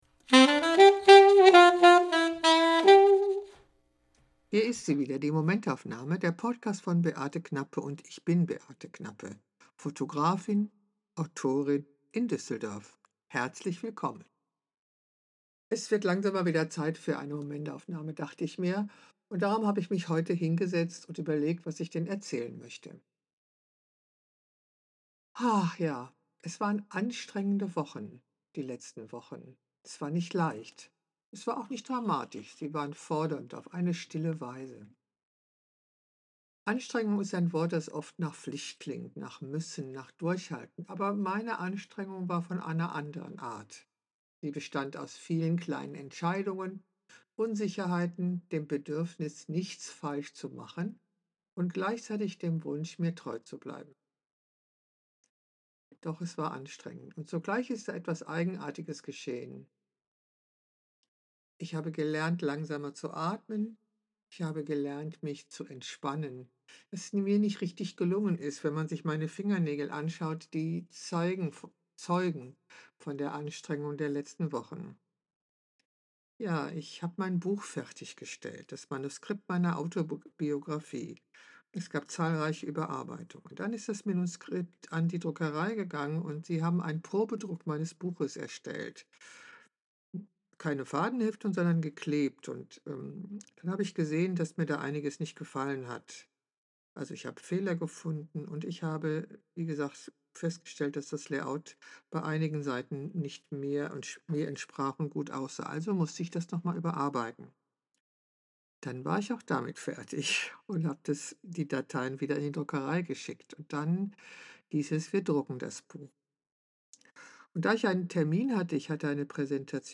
Ich lese aus meinem Buch vor.